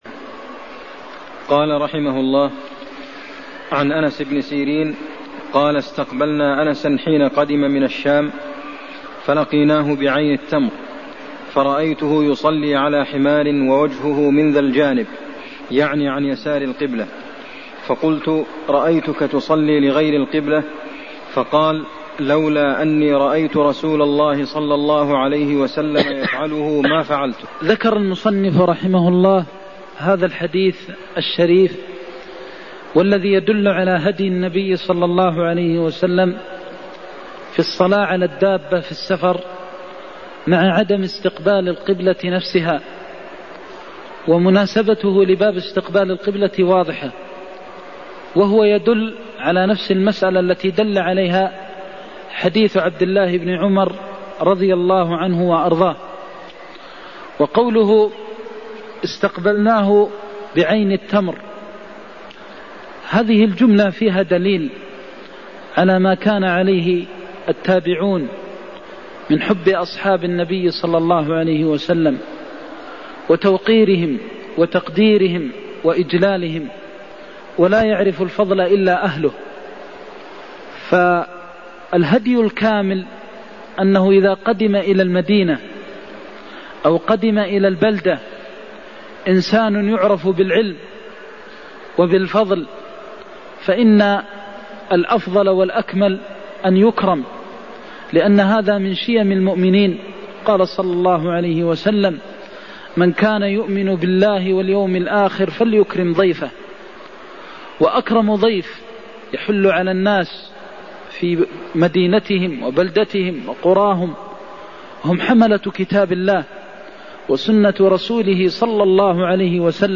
المكان: المسجد النبوي الشيخ: فضيلة الشيخ د. محمد بن محمد المختار فضيلة الشيخ د. محمد بن محمد المختار الصلاة على الراحلة إلى غير القبلة (66)